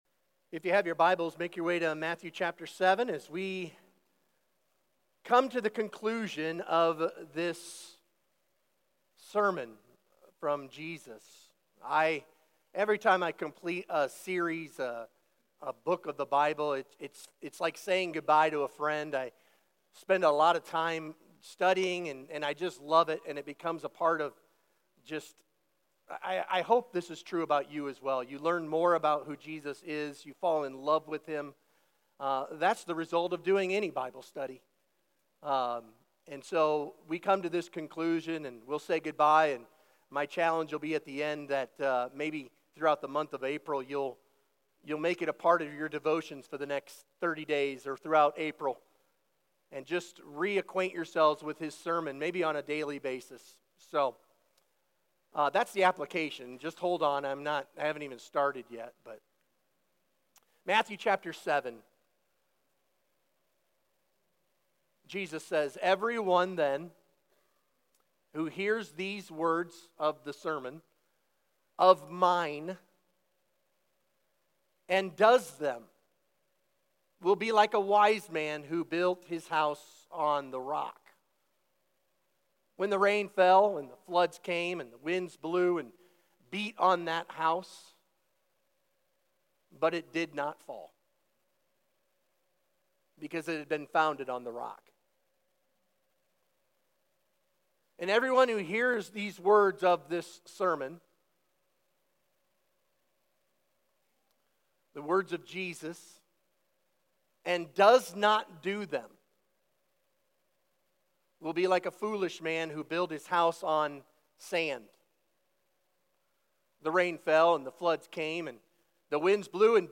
Sermon Questions Read Matthew 7:24-29 together.